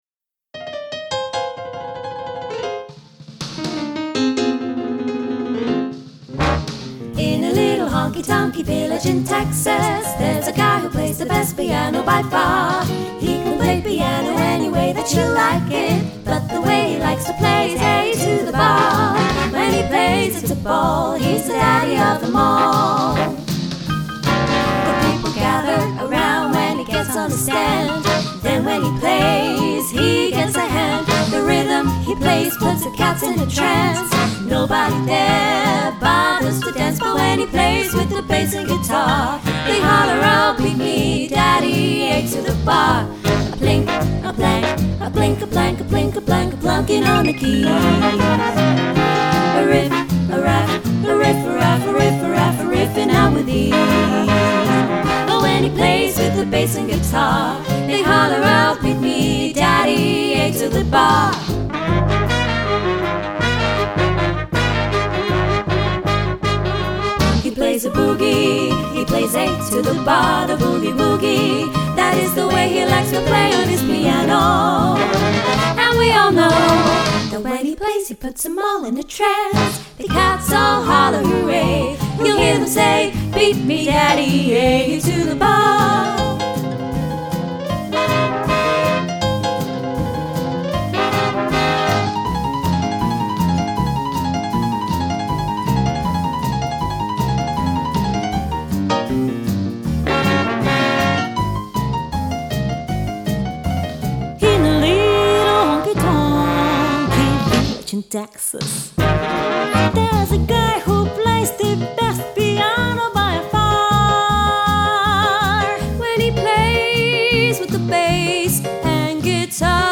Three Female Vocal Harmony Speakeasy Swing Band for Hire
3 x Vocalists, Backing Tracks